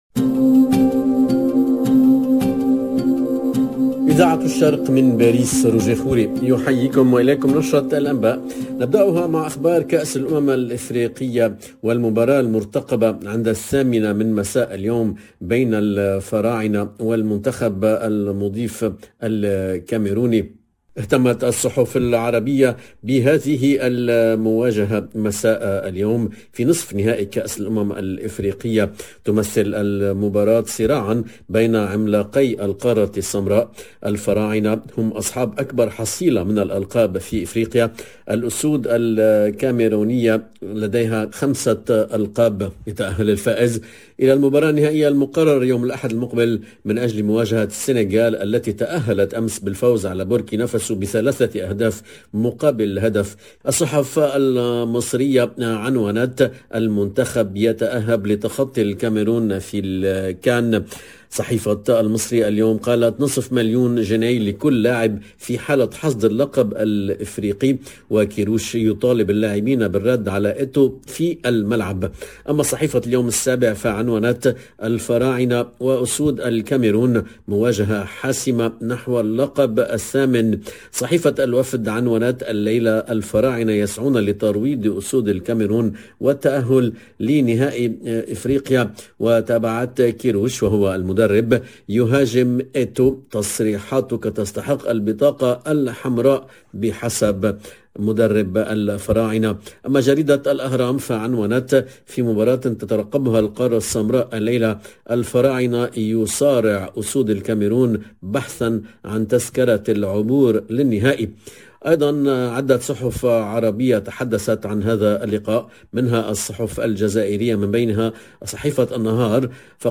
LE JOURNAL DE MIDI 30 EN LANGUE ARABE DU 3/02/22